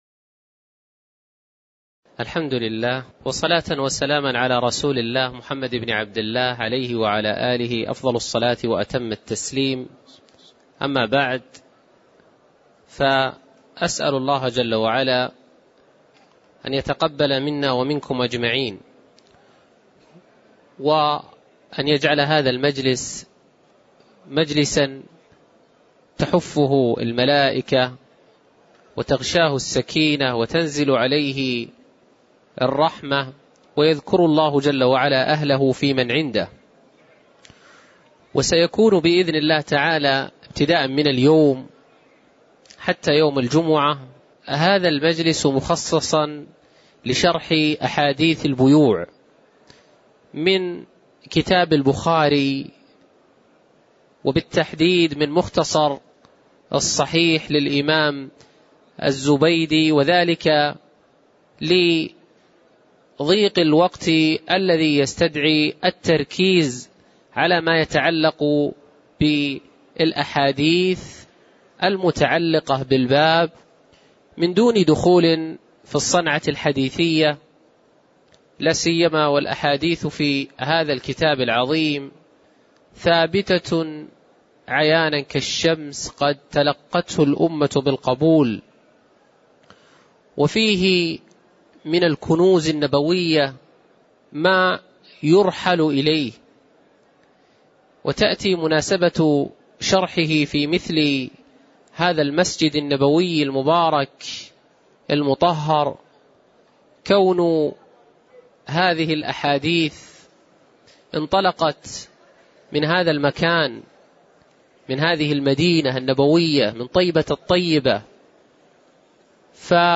تاريخ النشر ٣ جمادى الأولى ١٤٣٨ هـ المكان: المسجد النبوي الشيخ